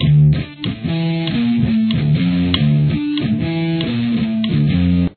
Palm mute the first note to keep the note from ringing.